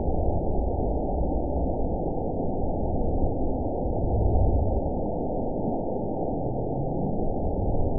event 916740 date 01/25/23 time 22:48:07 GMT (2 years, 3 months ago) score 8.77 location TSS-AB01 detected by nrw target species NRW annotations +NRW Spectrogram: Frequency (kHz) vs. Time (s) audio not available .wav